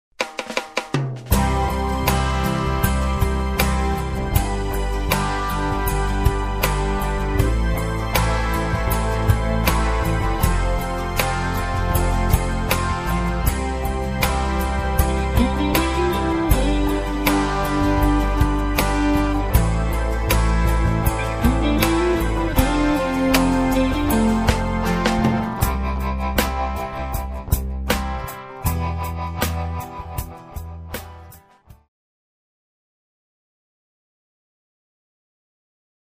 Karaoke Soundtrack
Backing Track without Vocals for your optimal performance.